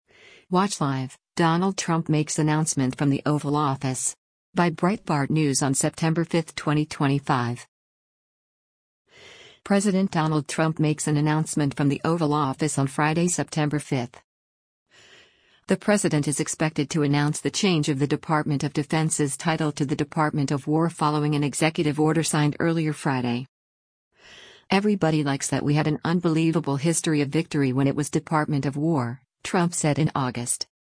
President Donald Trump makes an announcement from the Oval Office on Friday, September 5.